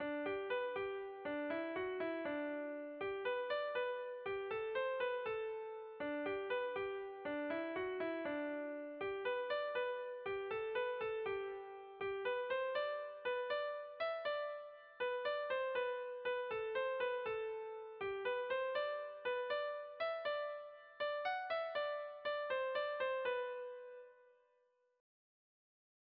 A-B-AB2CDC